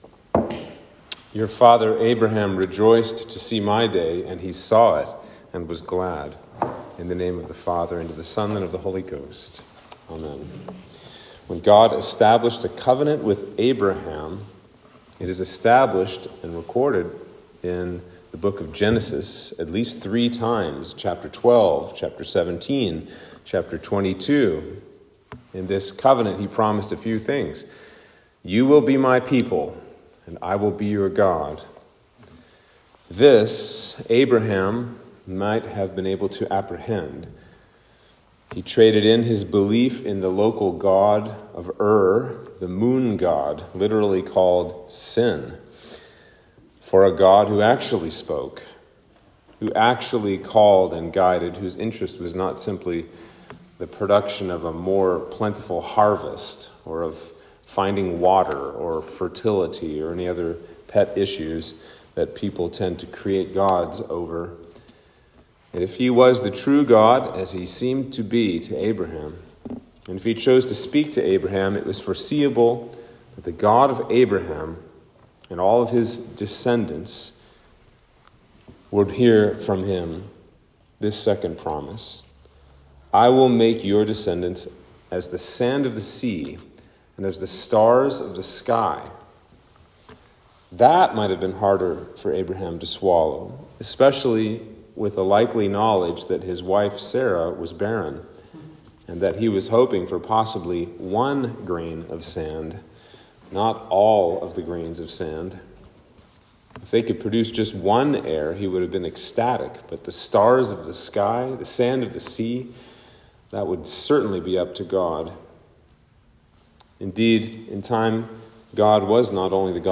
Sermon for Passion Sunday